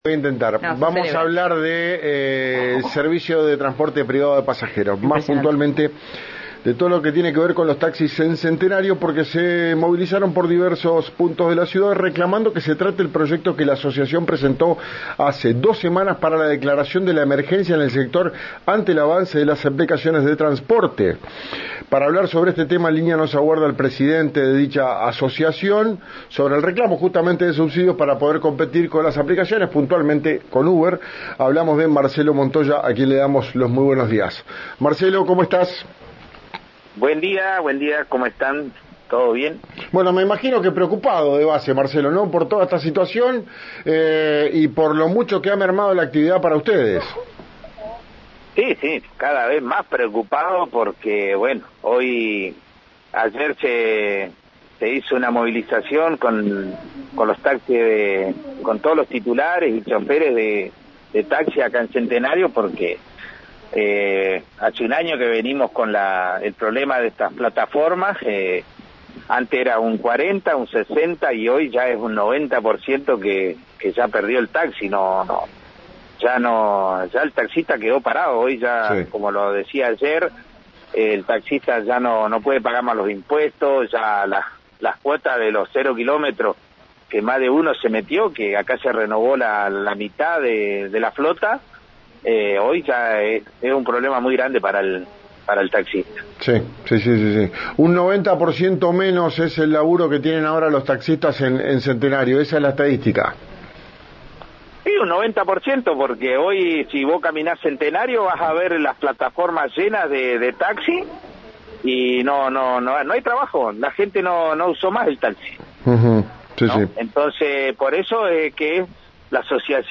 En diálogo con RÍO NEGRO RADIO, sostuvo que la actividad cayó en un 90% en el último año, lo que vuelve imposible para los trabajadores afrontar impuestos y las cuotas de los vehículos0 kilómetro.